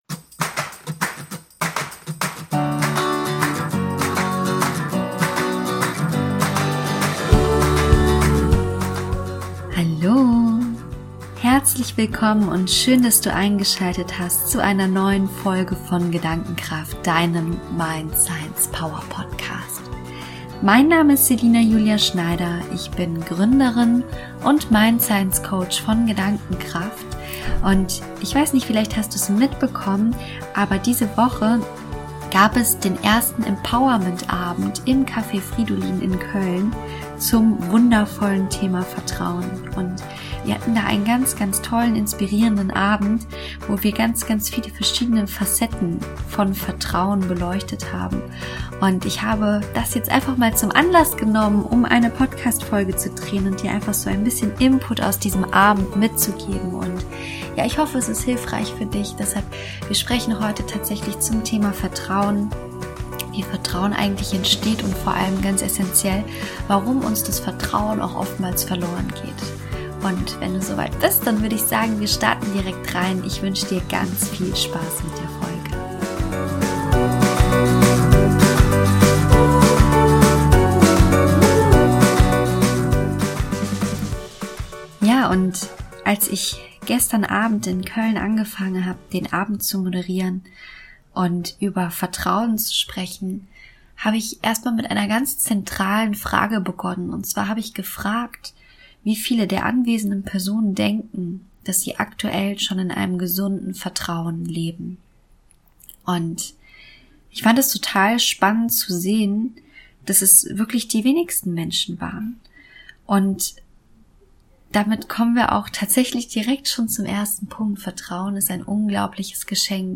Wie Du Dein Vertrauen heilen kannst - Highlight aus dem Mindful Evening in Köln - ~ Gedankenkraft - Dein Podcast aus dem Lebensloft Podcast
Hallo Du Kraftmensch, diese Woche habe ich beim ersten Mindful Evening in Köln zum Thema Vertrauen gesprochen. In der heutigen Podcastfolge teile ich daher mit Dir einen Teil der Inhalte von diesem wundervollen Abend.
Ich zeige Dir, wie Du diese Erfahrungen in Deinem Leben auch lokalisieren kannst und mit Hilfe des Gedankenkraftrads achtsam betrachten, verstehen und dann letztendlich auch heilen kannst. Insbesondere die geführte Meditation ist ein so schöner Weg, genau diese Erfahrungen zu heilen und gibt uns die Möglichkeit neu zu wählen, wie wir uns in unserem Leben ausrichten möchten.
Gedankenkraft__32_Empowernentabend_in_Koeln.mp3